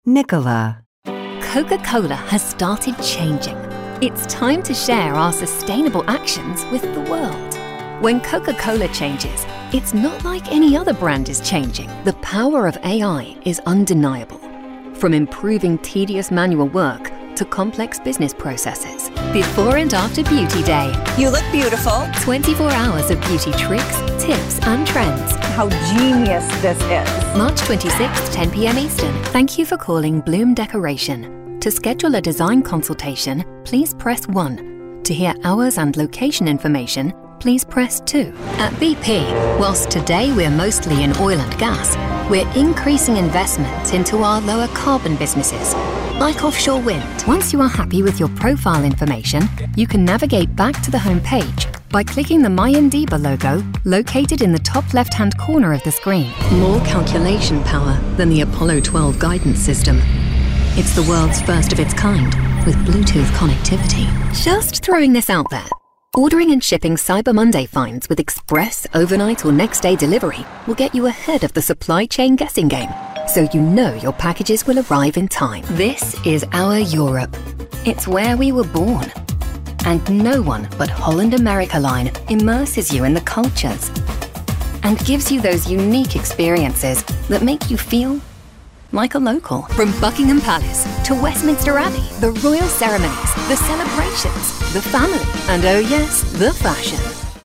Showcase Demo